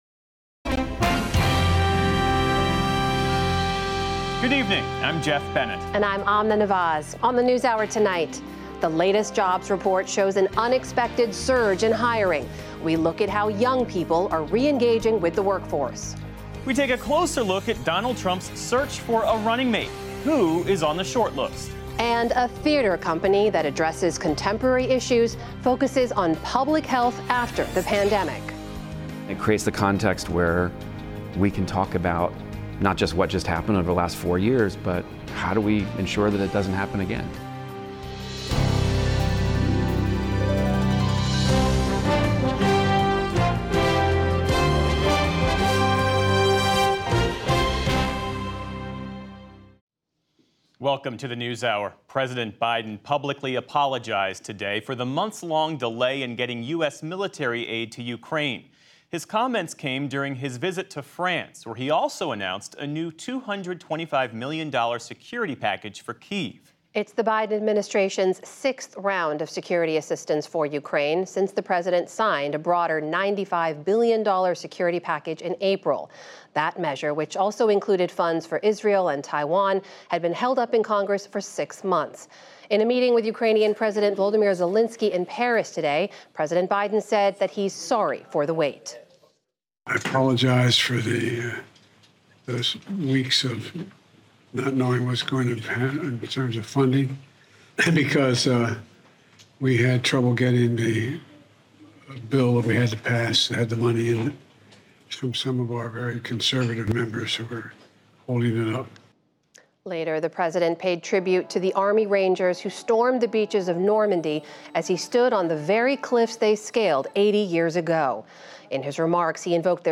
June 7, 2024 - PBS NewsHour full episode